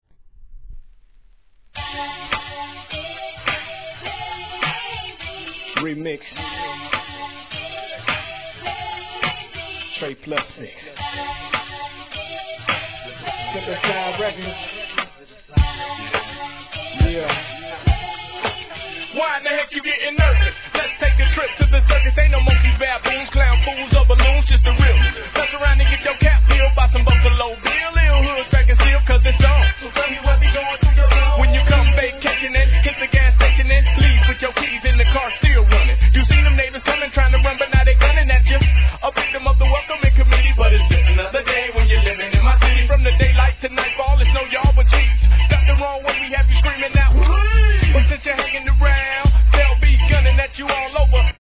G-RAP/WEST COAST/SOUTH
シンセの爽快なメロ〜トラックに賑やかに盛り上げるフックも最高!!